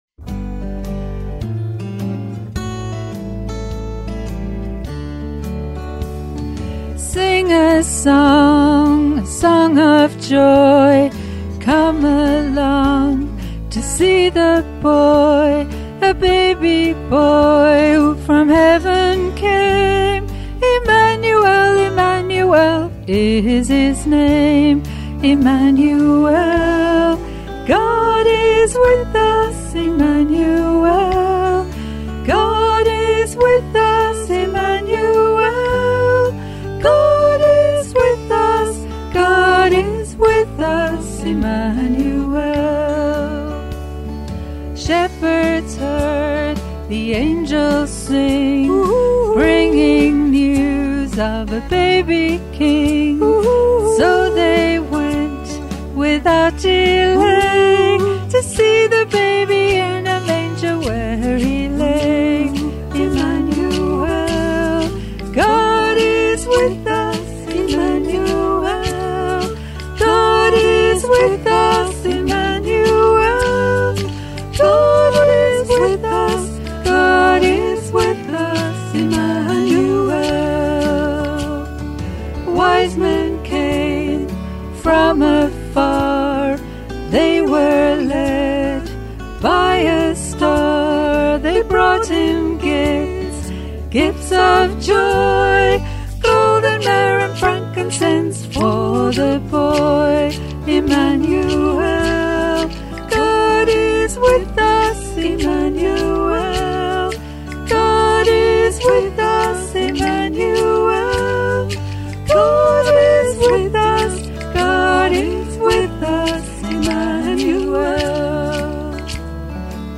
A Christmas greeting in the form of a song
on backing vocals and percussion.